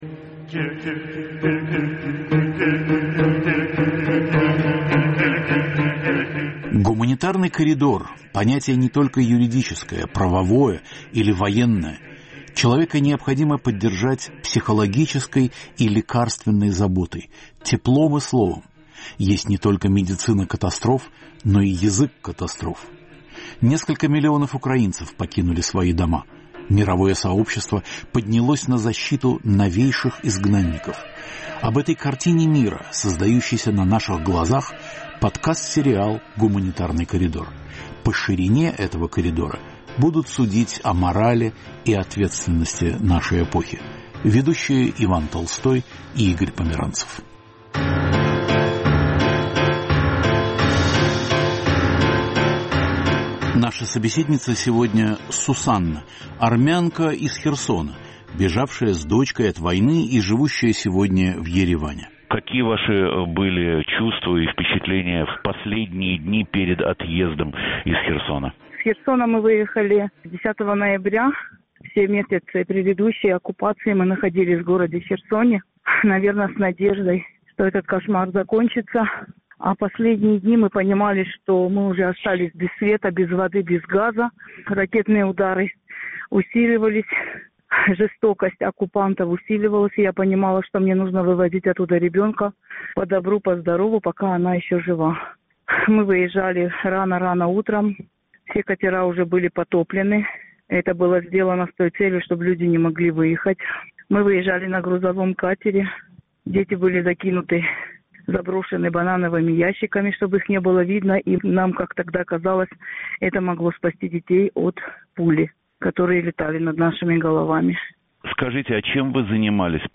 В эфире "Свободы" – беженки из Одессы в Ереван